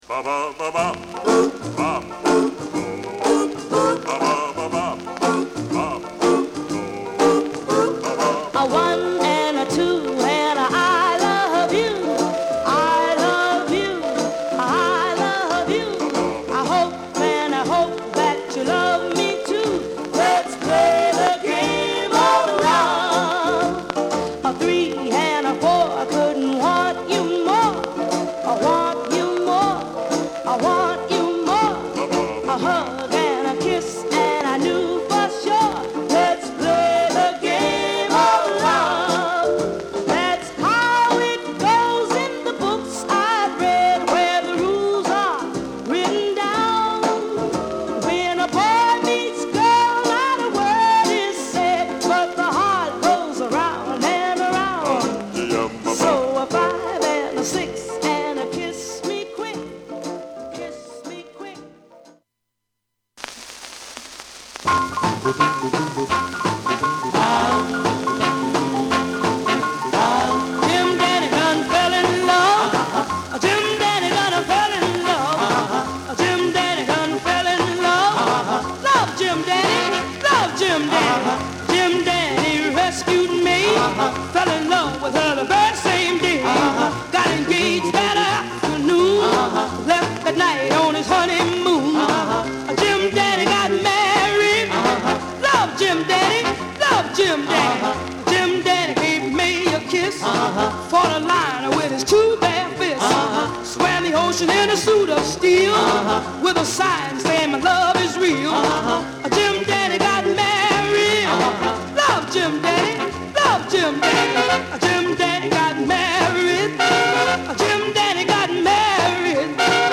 R&B / SOUL / R&R /BULES